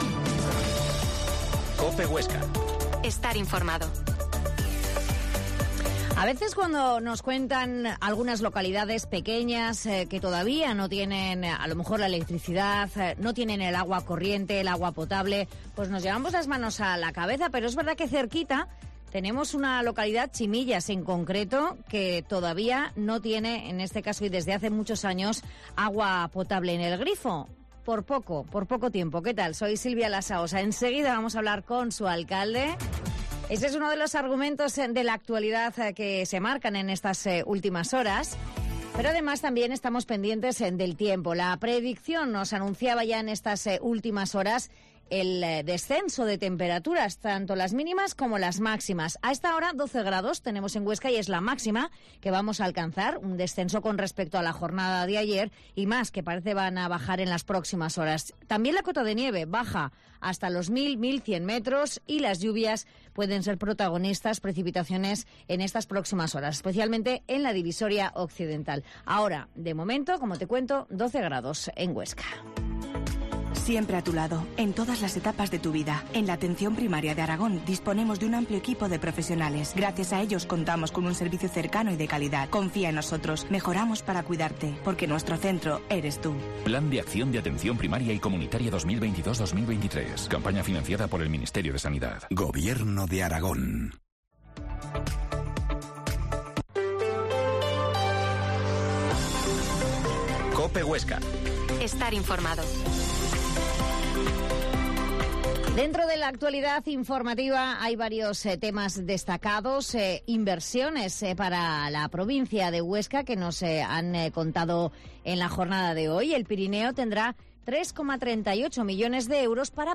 Mediodia en COPE Huesca 13.50 Entrevista al alcalde de CHimillas, Miguel Angel Torres